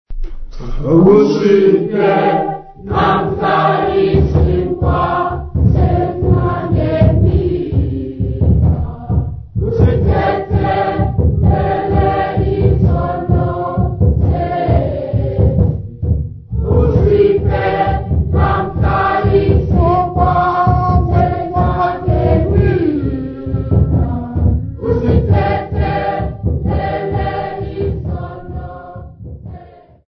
Folk music
Sacred music
Africa South Africa Lady Frere, Eastern Cape sa
field recordings
Church choral hymn, with singing accompanied by drumming. New Zulu Church service hymn.